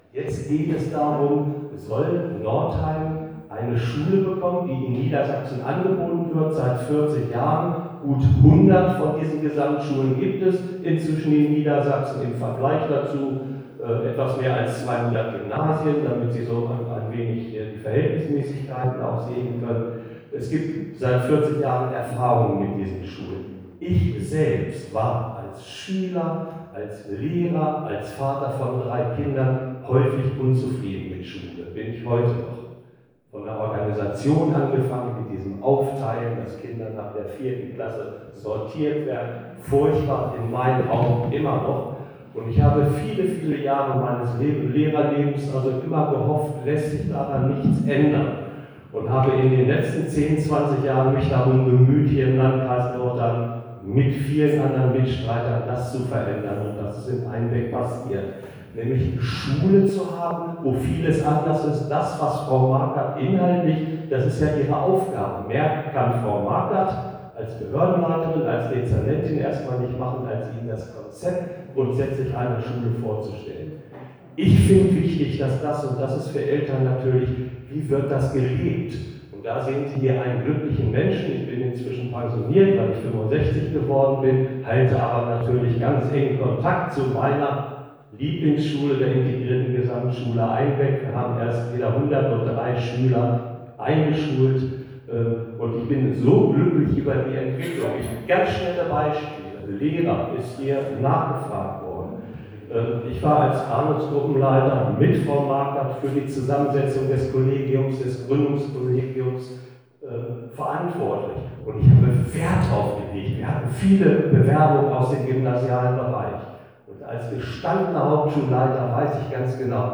Offizielle IGS-Informationsveranstaltung des Landkreises am 9.9. in der Thomas-Mann-Schule